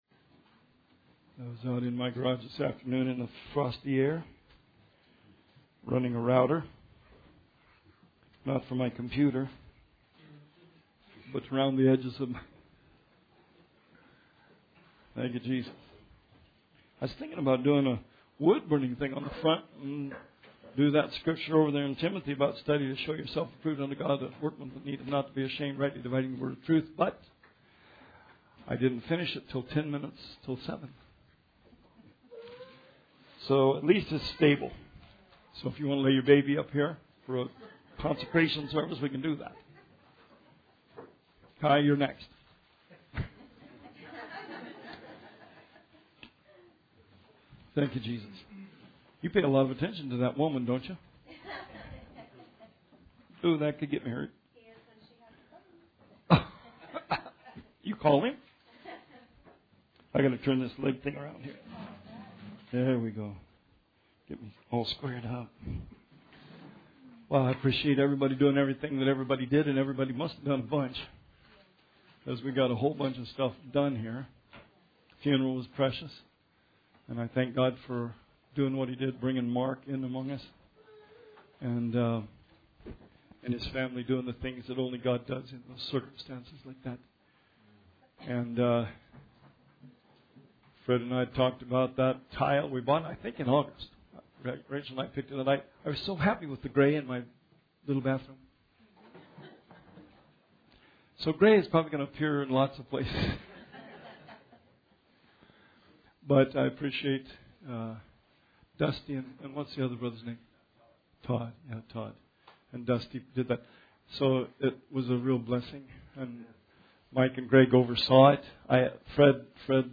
Bible Study 1/23/19